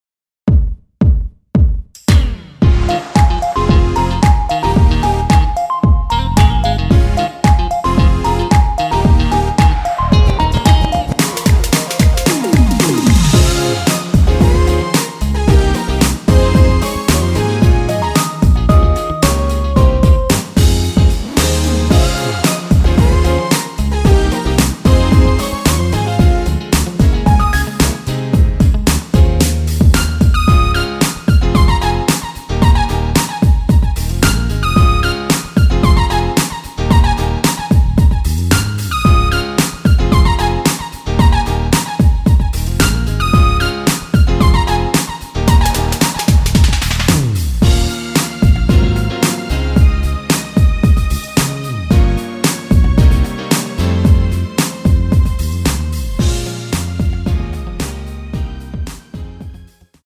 Ab
◈ 곡명 옆 (-1)은 반음 내림, (+1)은 반음 올림 입니다.
앞부분30초, 뒷부분30초씩 편집해서 올려 드리고 있습니다.